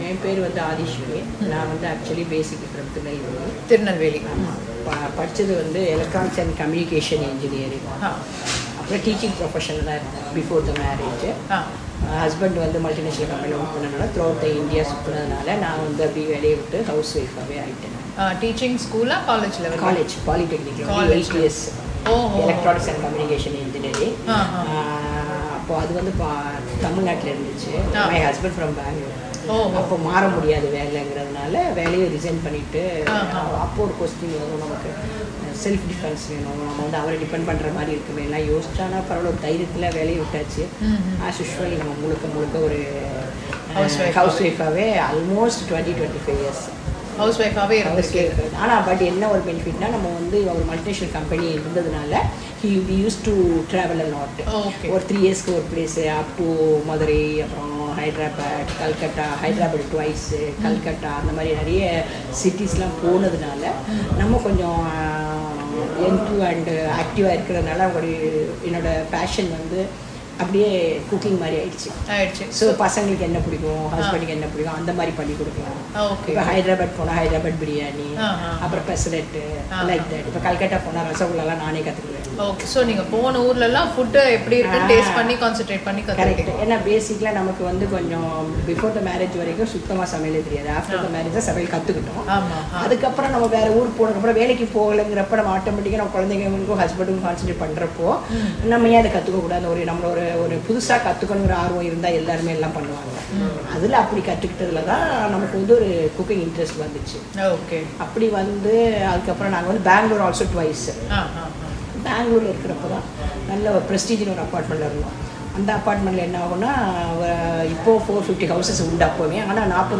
நேர்காணல்கள்